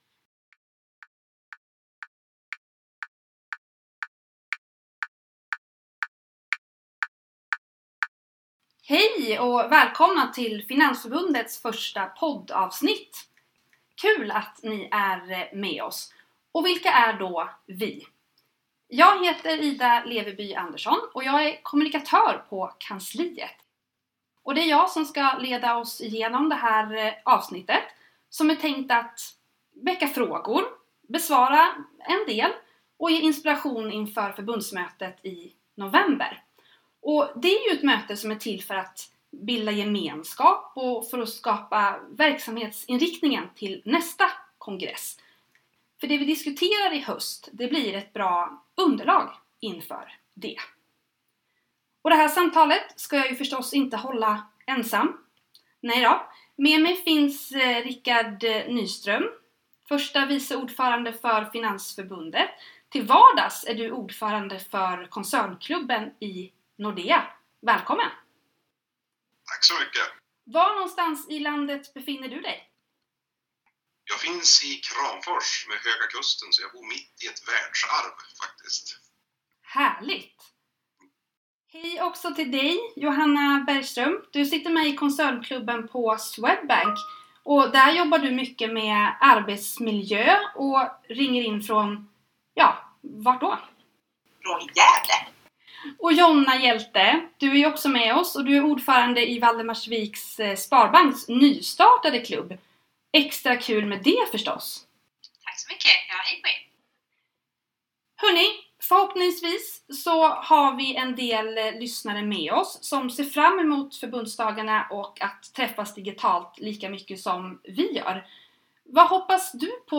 Samtal om rollen som förtroendevald, podd